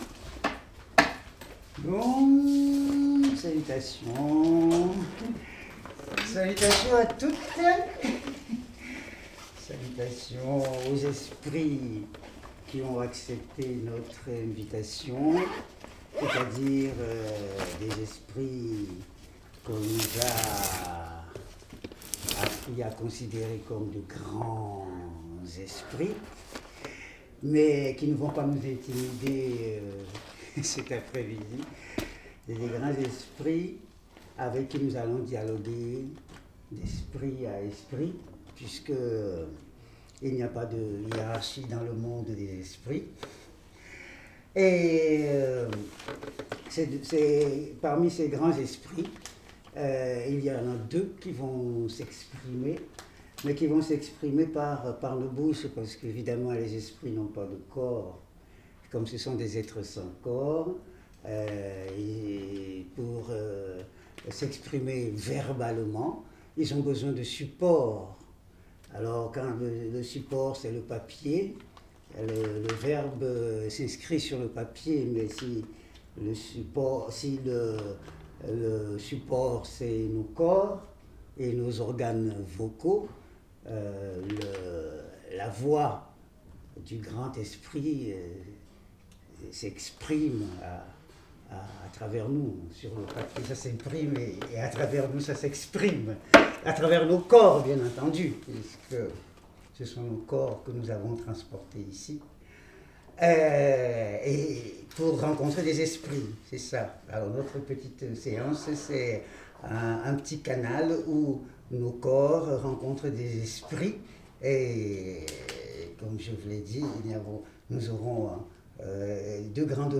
Cérémonie de divination poétique animée par Kossi Efoui, auteur. L’auteur dit un poème.